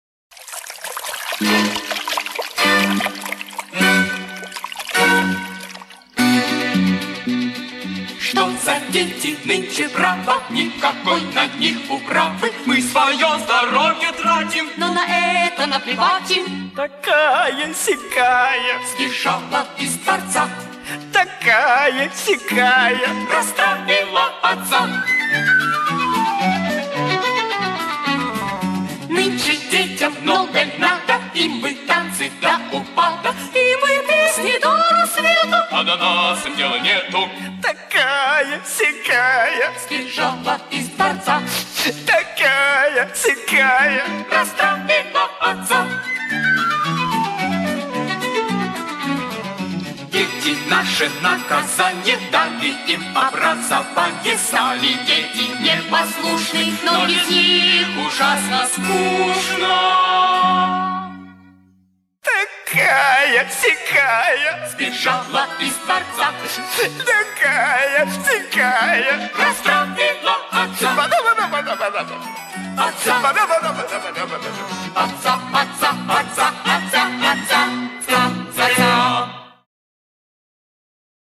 Главная » Музыка для детей » Детские песенки